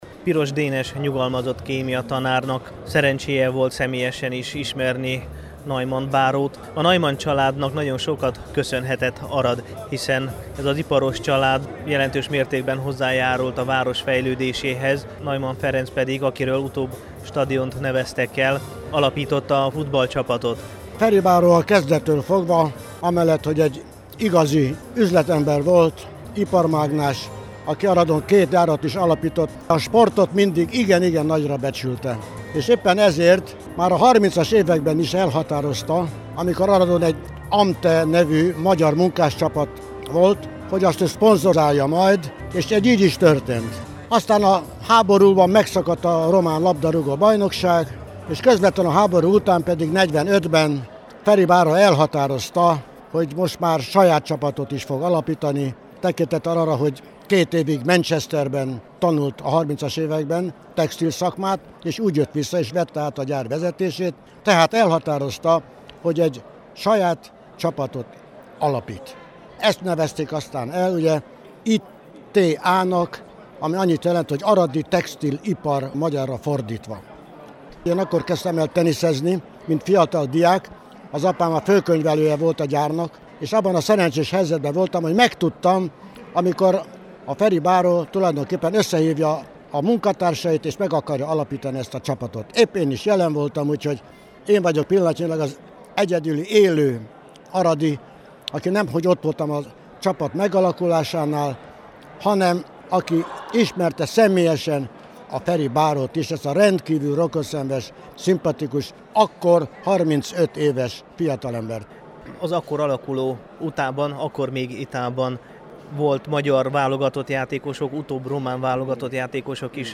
Az UTA alapításának 70. évfordulója alkalmából szervezett hétvégi rendezvényen nem csak az egykor szebb napokat megélt futballklub legendás játékosaival találkozhatott az érdeklődő, hanem olyanokkal is, akik az egykori textilgyári egylet születésénél is bábáskodtak.